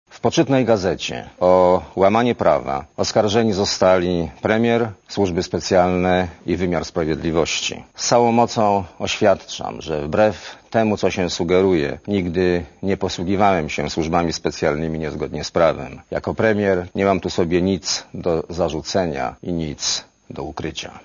Mówi premier Leszek Miller (86 KB)